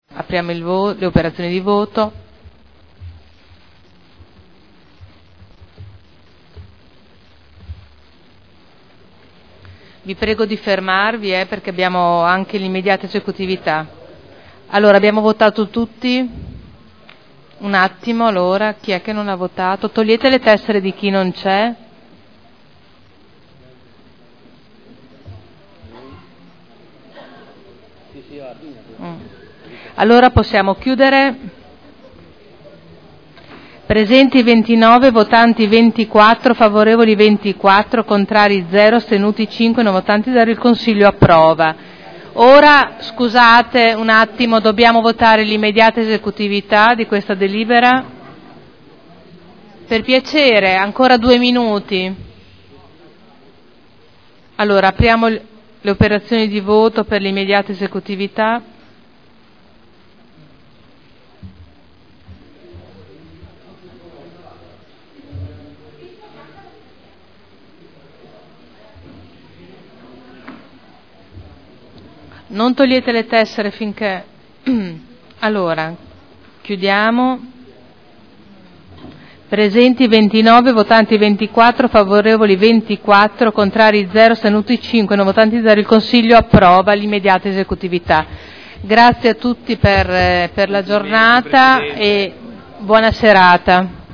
Seduta del 21/03/2011. Mette ai voti proposta di deliberazione: Diritto di superficie a favore della Cooperativa Spazio Unimmobiliare per gli impianti ubicati presso la Polisportiva Saliceta San Giuliano e presso la Polisportiva Gino Nasi – Autorizzazione a iscrivere ipoteca” e immediata esecutività.